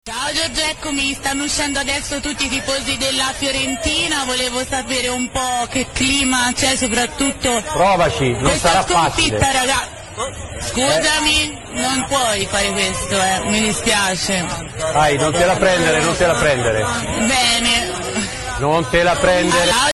Giornalista molestata in diretta, il conduttore commenta: “Non prendertela”